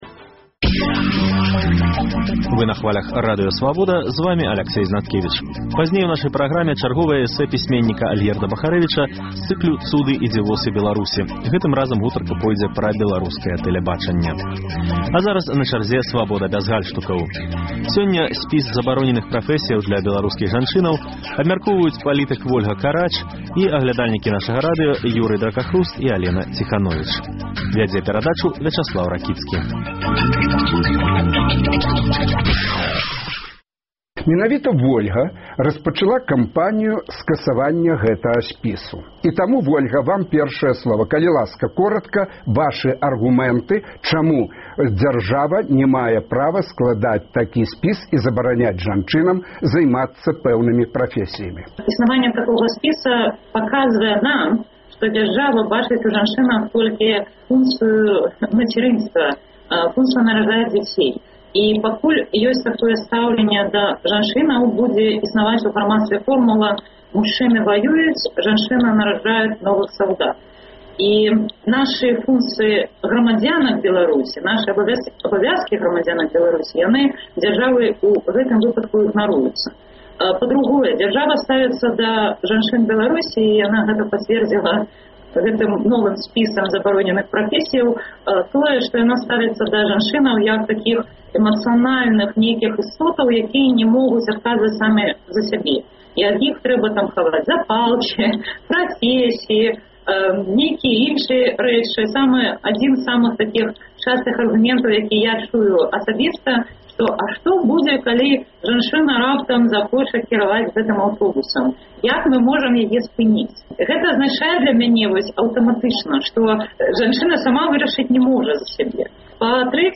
У дыскусіі бяруць удзел: у праскай тэлестудыі Радыё Свабода журналісты